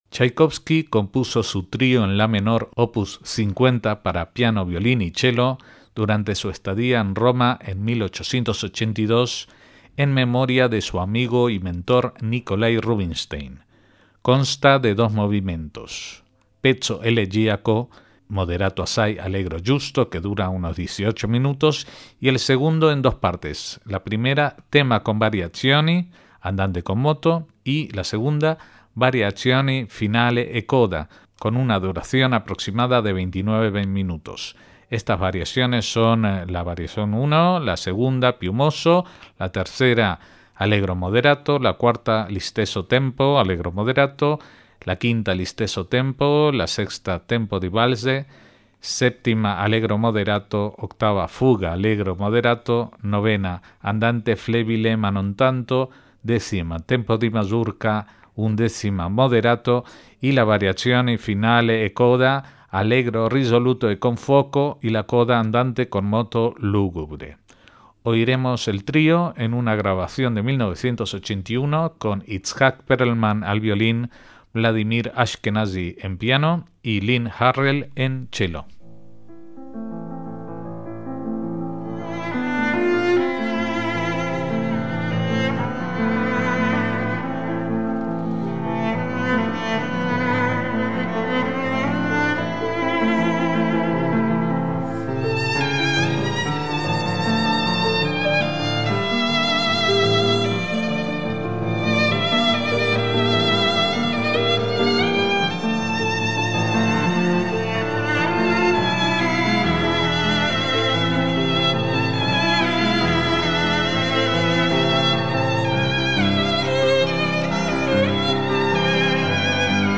MÚSICA CLÁSICA
La partitura incluye piano, violín y chelo